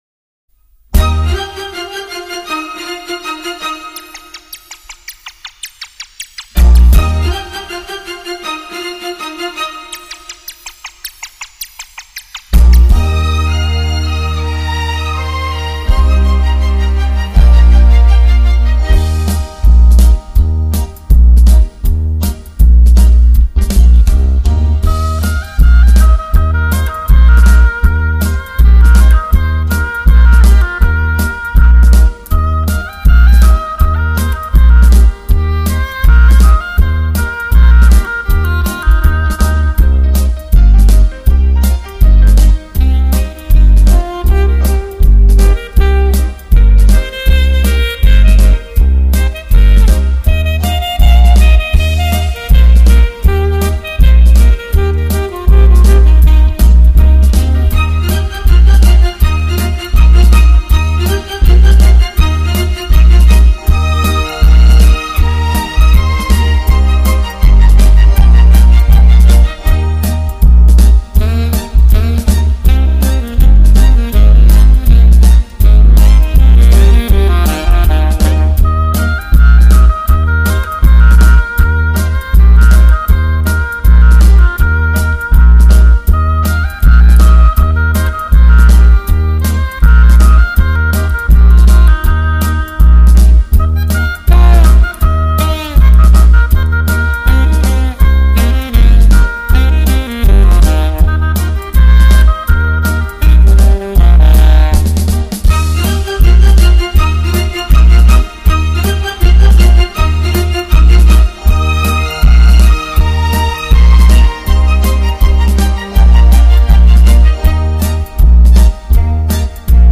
在空气中蔓延，旋律飘起时那柔柔软软的声音，如雨点轻敲着屋檐，有一种熟悉的味道。
24BIT HI—FI音乐系列，带你回到最精彩的旋律中！